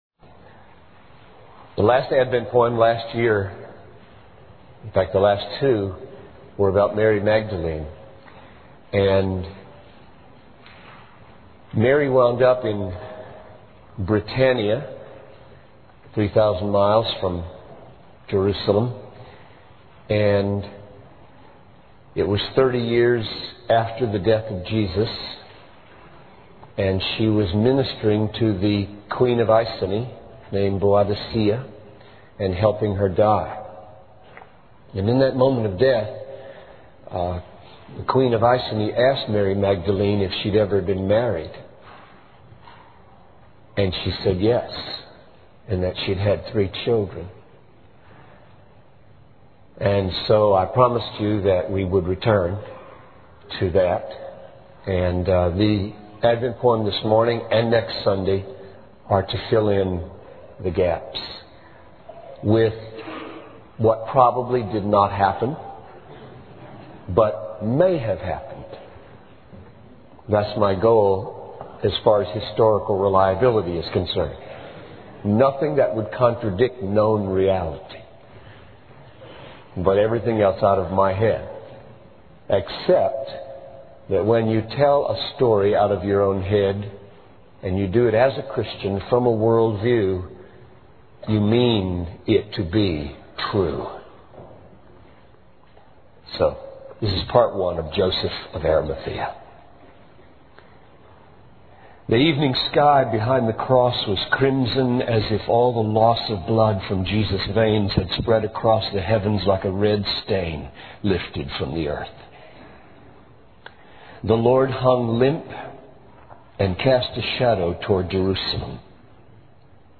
In this sermon transcript, the speaker reflects on the lack of courage and faith among the people of Jerusalem, particularly those who had been with Jesus. The focus then shifts to Joseph, a man who defies societal expectations and risks his life to honor Jesus.